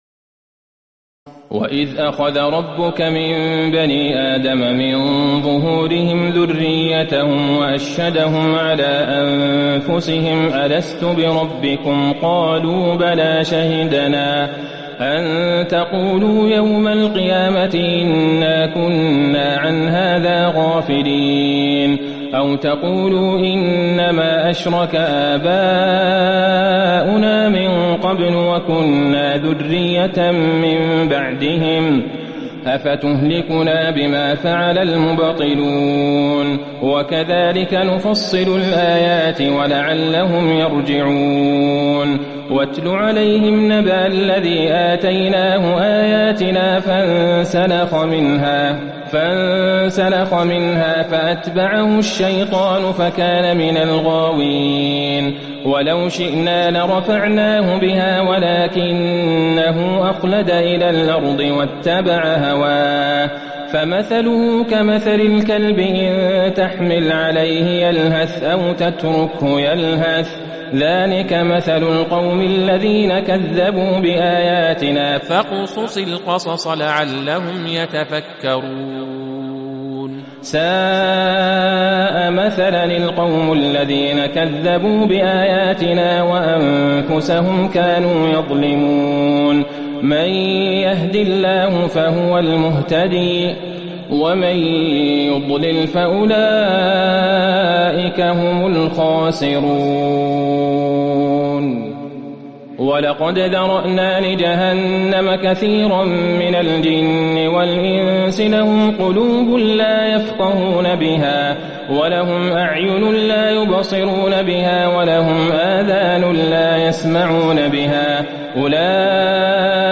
تراويح الليلة التاسعة رمضان 1438هـ من سورتي الأعراف (172-206) والأنفال (1-40) Taraweeh 9 st night Ramadan 1438H from Surah Al-A’raf and Al-Anfal > تراويح الحرم النبوي عام 1438 🕌 > التراويح - تلاوات الحرمين